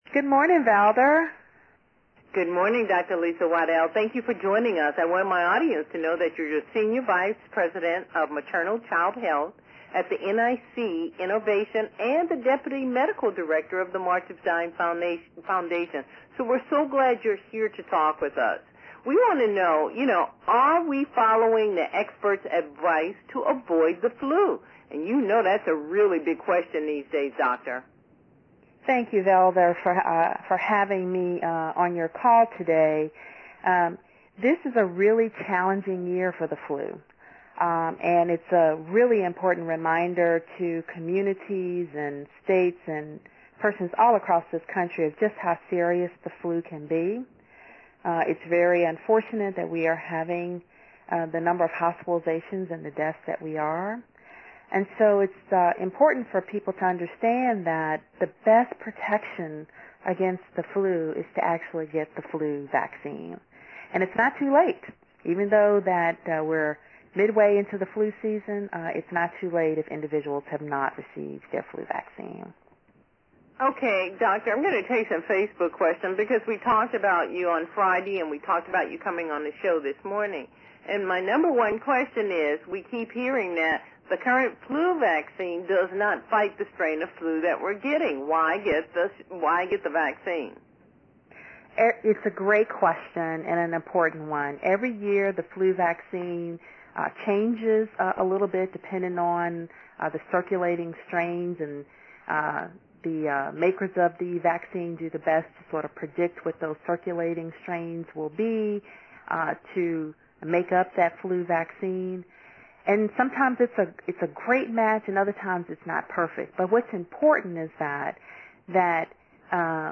Radio interviews:
9:10 am: ten minute live interview on ValderBeeBee Show (Dallas, TX).